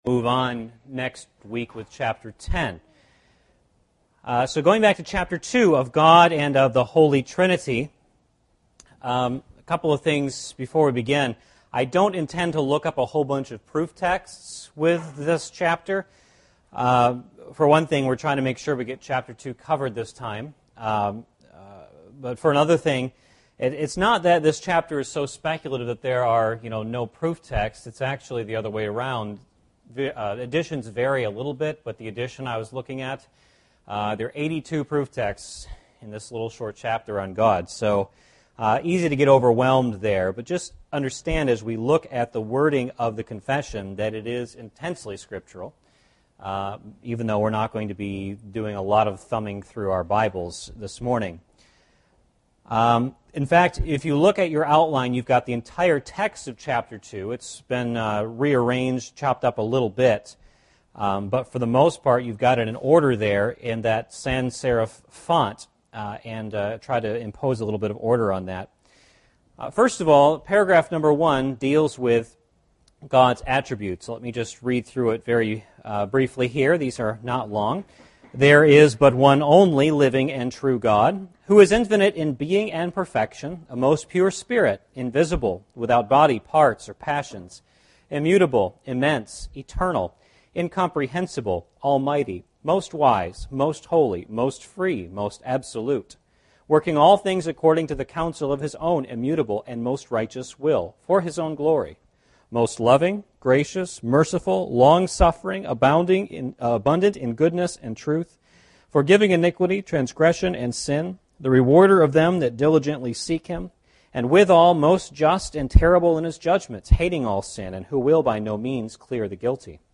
Service Type: Sunday School « Let No One Boast in Men!